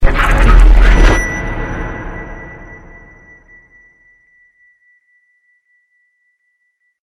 EyesJumpscareSound.wav